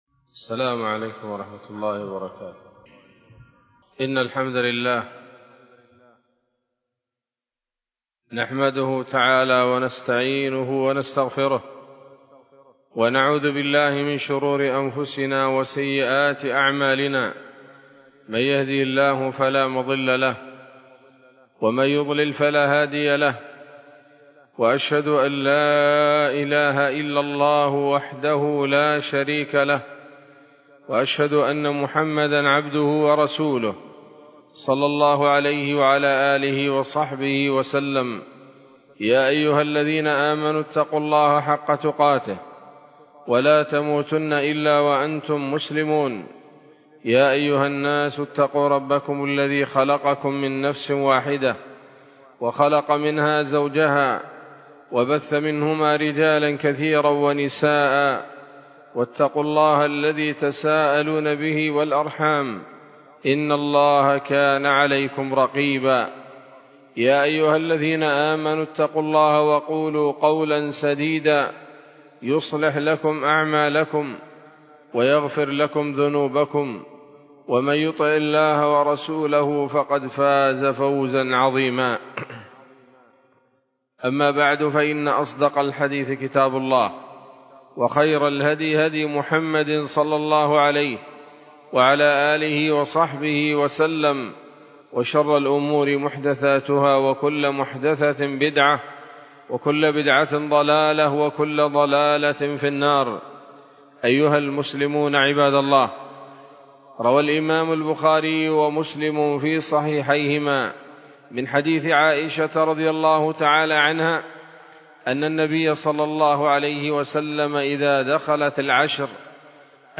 خطبة جمعة بعنوان: (( اللف والنشر لبعض فضائل الليالي العشر )) 21 رمضان 1446 هـ، دار الحديث السلفية بصلاح الدين
خطبة-جمعة-بعنوان-اللف-والنشر-لبعض-فضائل-الليالي-العشر.mp3